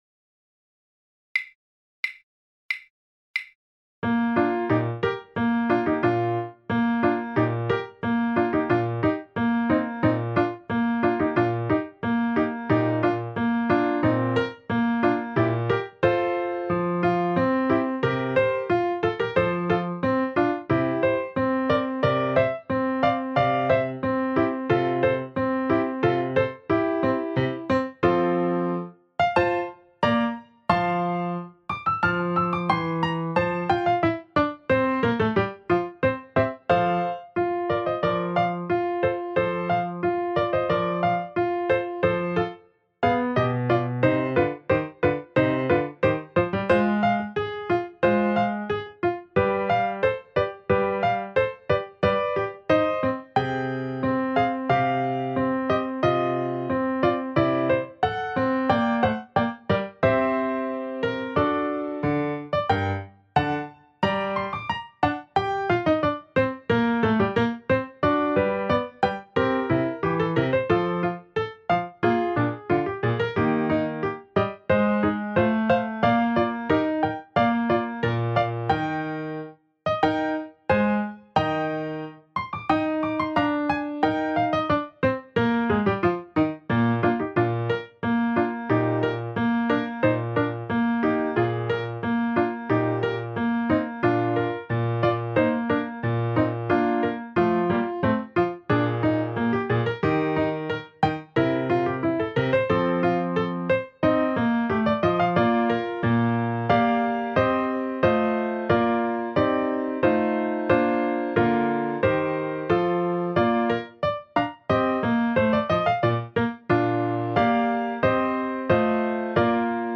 Recorded piano accompaniment backing tracks
FOR TWO FLUTES AND PIANO
SAMPLE BACKING TRACK: